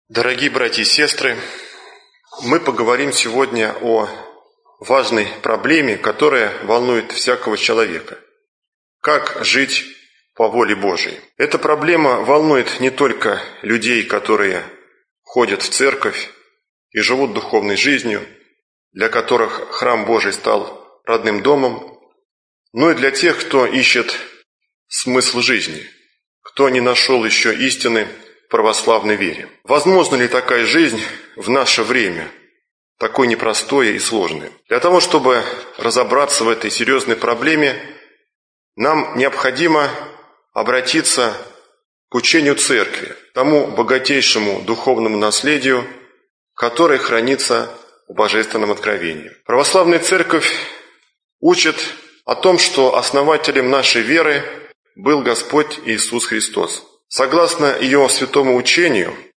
Аудиокнига Как узнать волю Божию | Библиотека аудиокниг